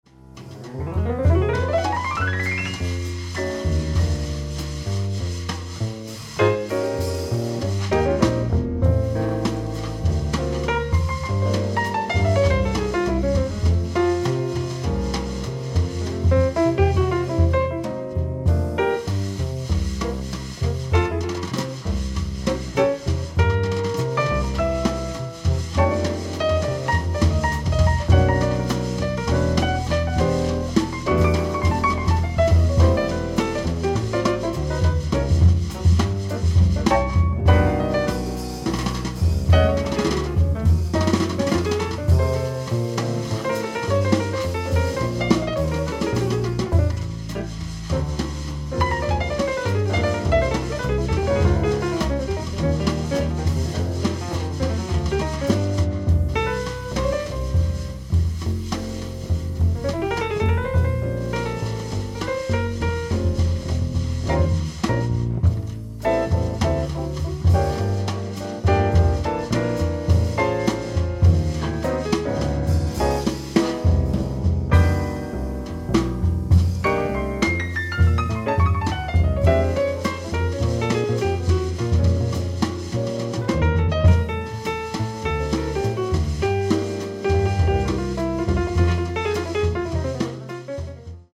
※試聴用に実際より音質を落としています。
violin